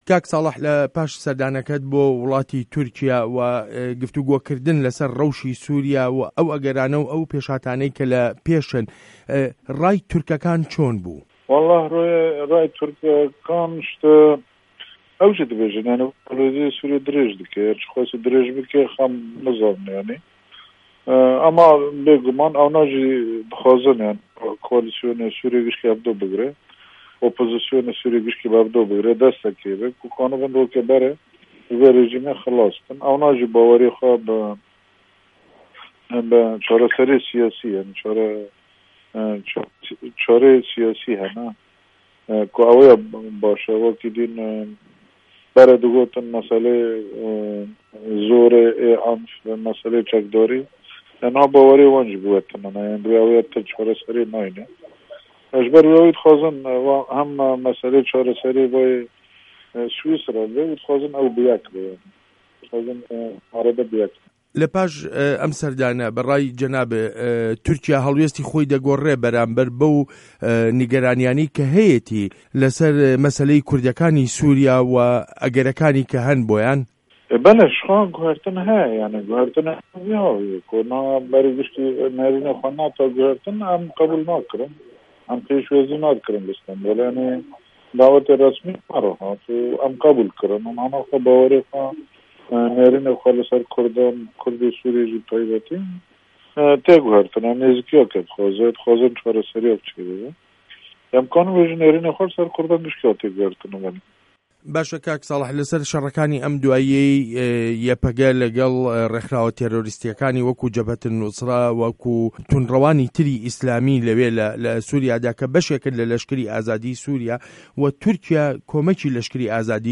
وتووێژ له‌گه‌ڵ ساڵح موسلم